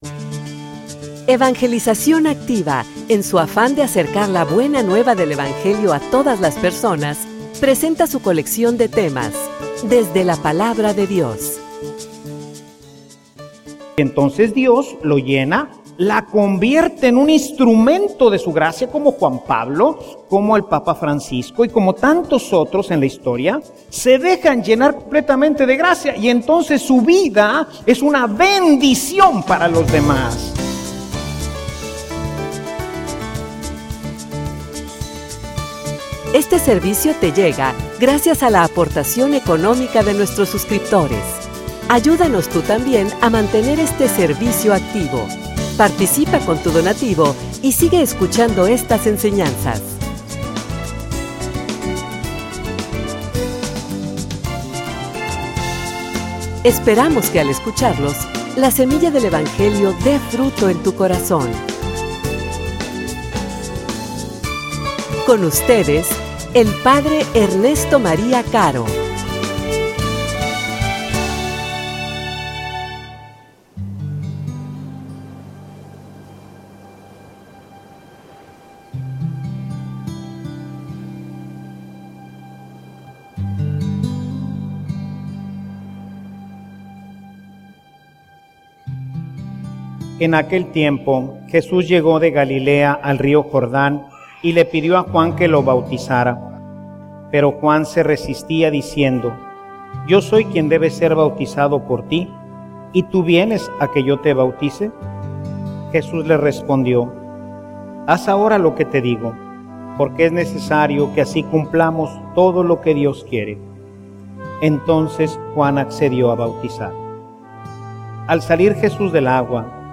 homilia_Humildad_y_obediencia.mp3